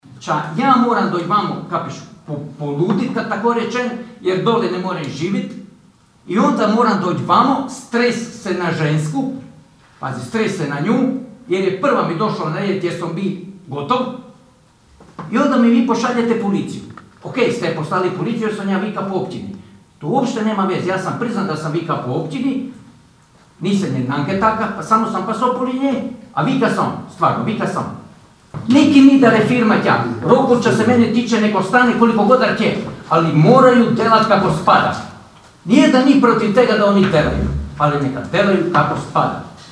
Nakon dugo vremena, a što je nekada bilo uobičajeno, sinoć se na sjednici Općinskog vijeća Pićna opet govorilo o Rockwoolu. Naime, sjednici su prisustvovali neki od nezadovoljnih građana kojima je bilo dozvoljeno obratiti se vijećnicima.